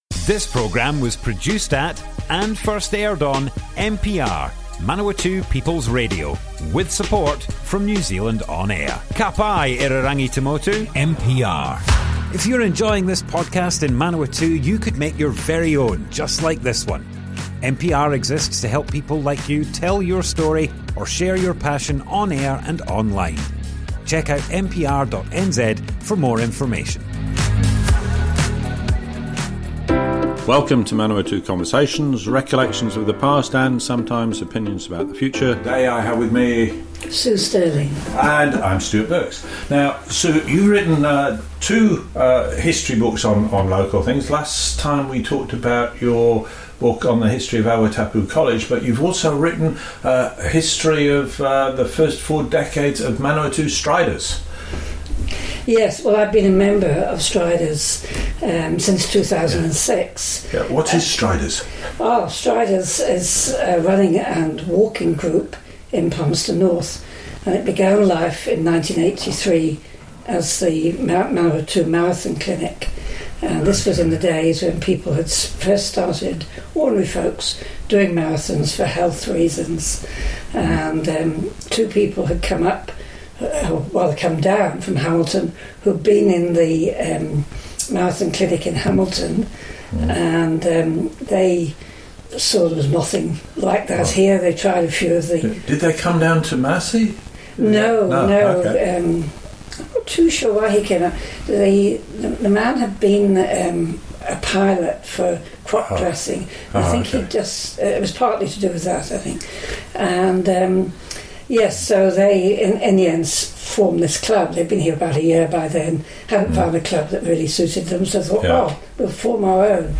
Manawatu Conversations More Info → Description Broadcast on Manawatu People's Radio, 23rd July 2024.
oral history